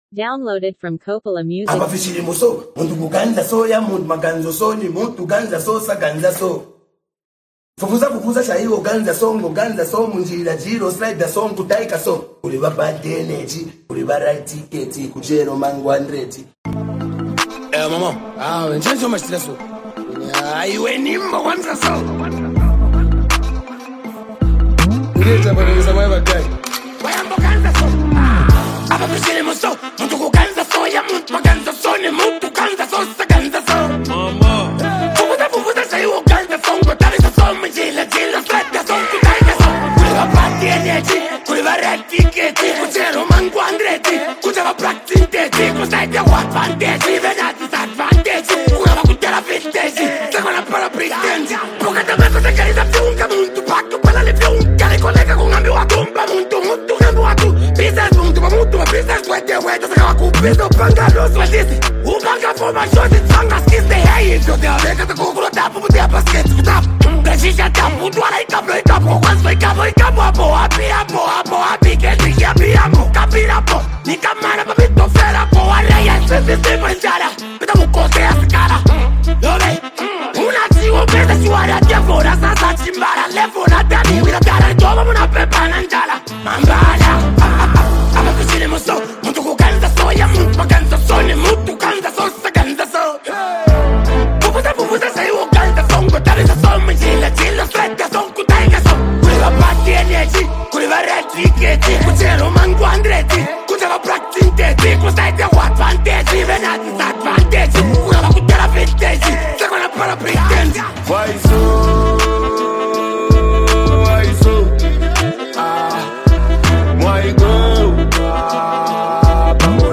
is a collaborative Afro-inspired track